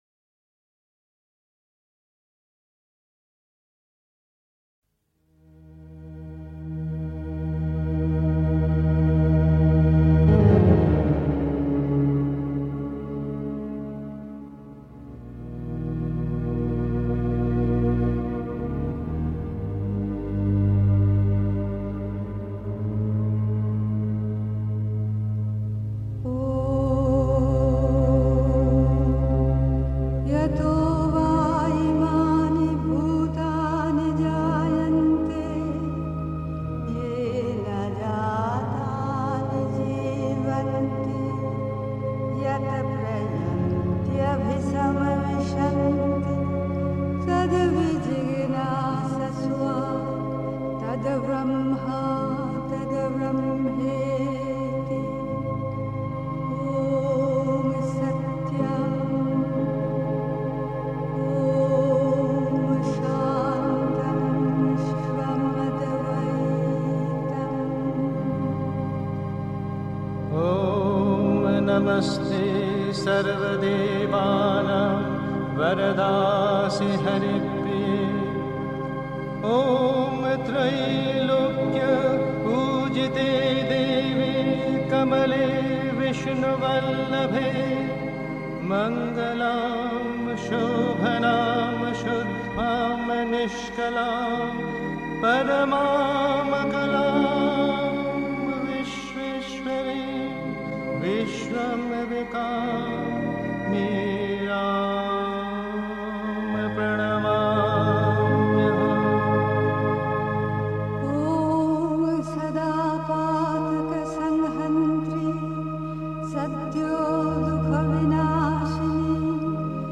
Pondicherry. 2. Seiner Eingebung folgen (Die Mutter, Weisse Rosen, 19. Februar 1959) 3. Zwölf Minuten Stille.